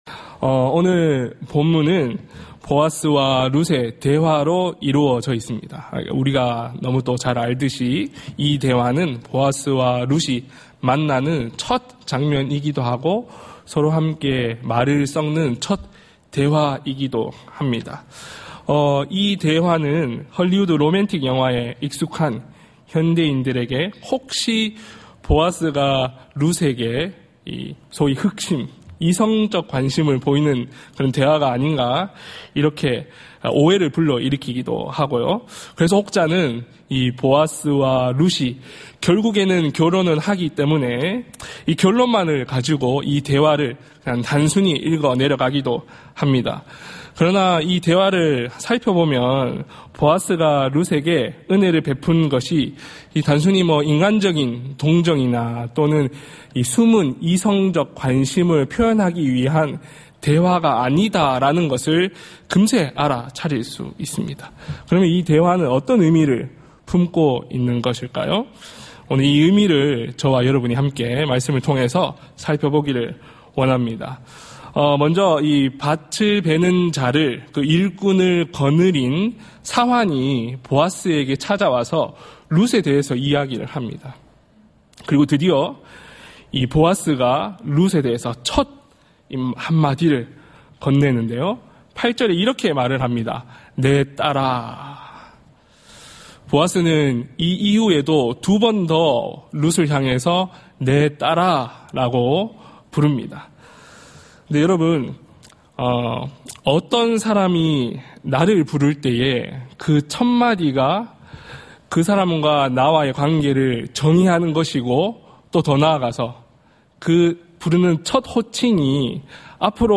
예배 수요예배